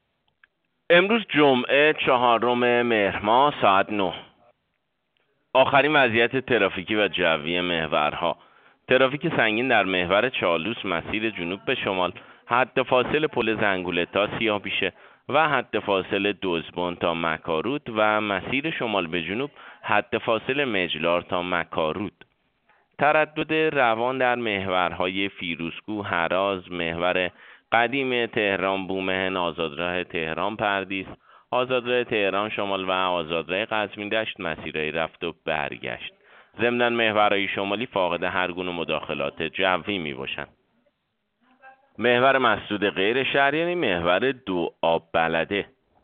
گزارش رادیو اینترنتی از آخرین وضعیت ترافیکی جاده‌ها ساعت ۹ چهارم مهر؛